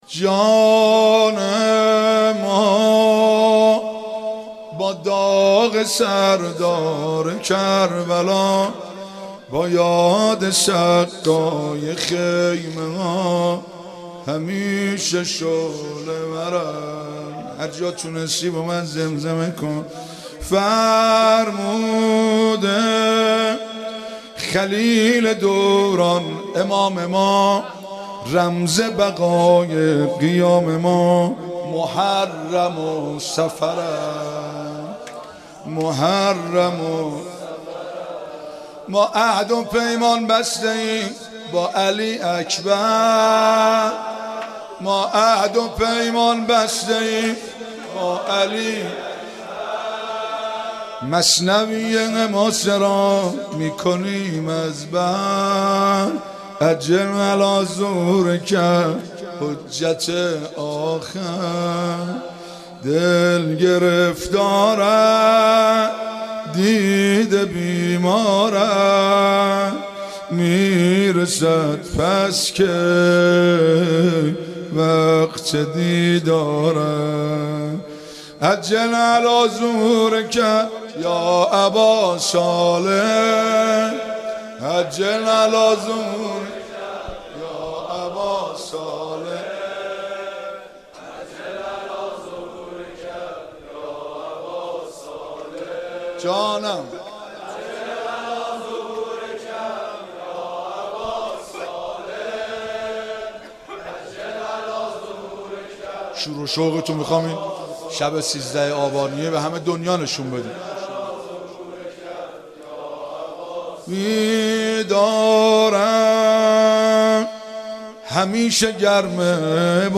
بزرگداشت